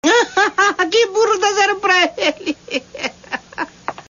Chaves debocha e fala seu bordão na escola: 'Que Burro, Dá Zero Pra Ele Hahaha'